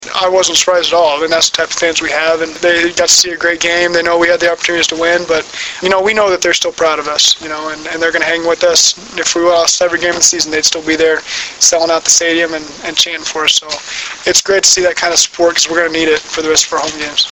Press Conference Audio Links (Oct. 21)